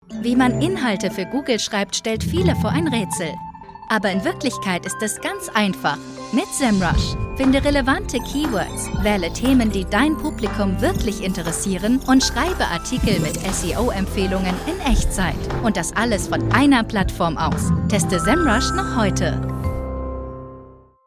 Female
EXPLAINER_ATRIFY.mp3
Microphone: Neumann TLM103, Rode NT1 A